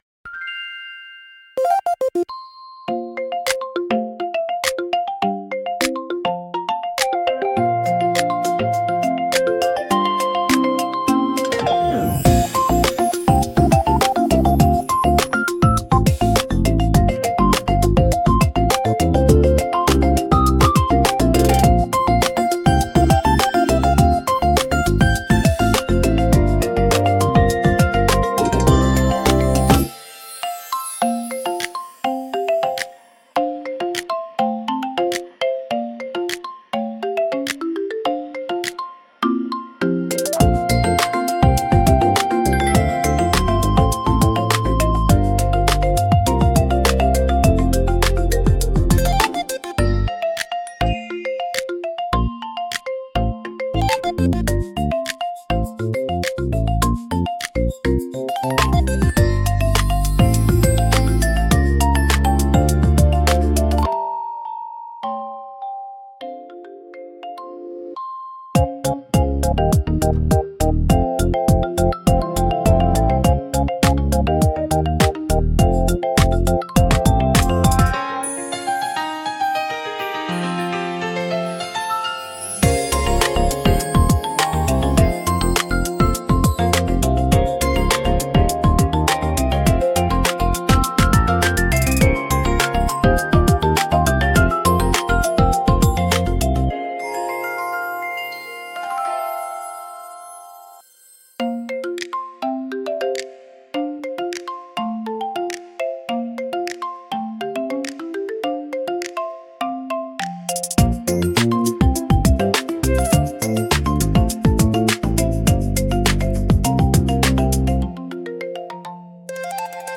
ゆめかわいいフリーBGM🧸🎧🫧
軽やかで可愛いゲーム風BGM